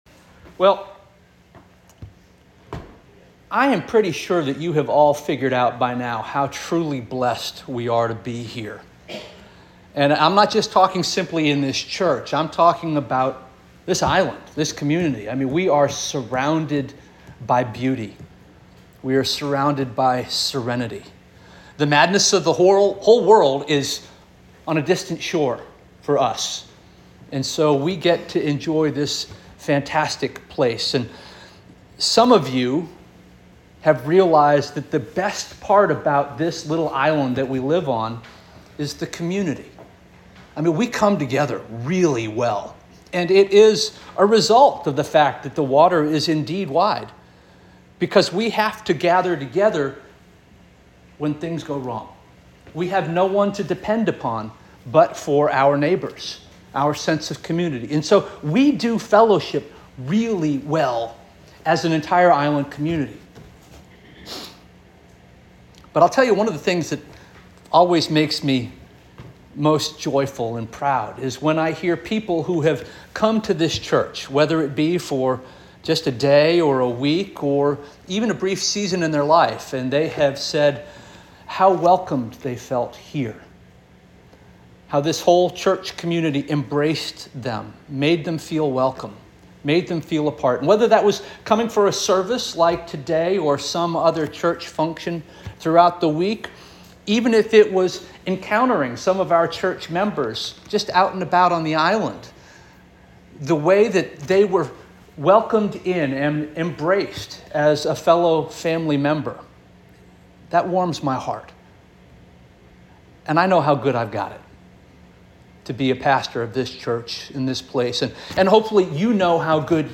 April 28 2024 Sermon